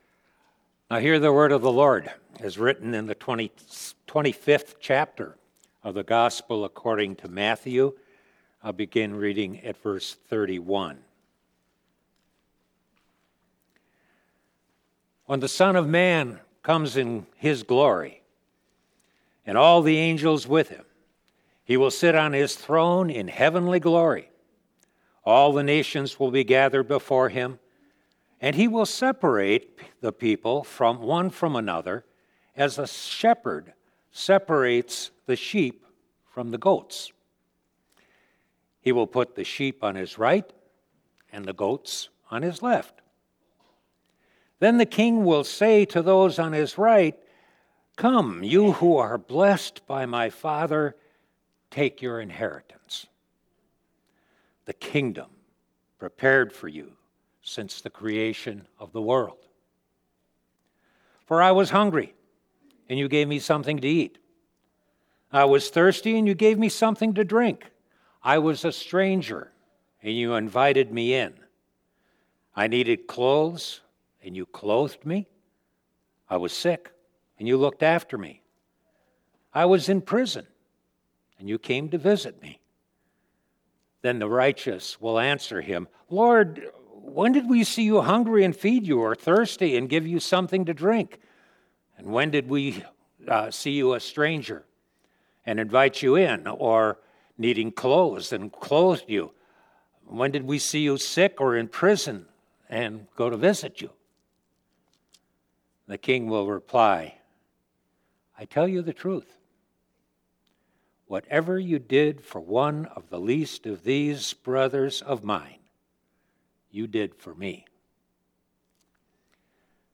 Bible Text: Matthew 25:31-40 | Preacher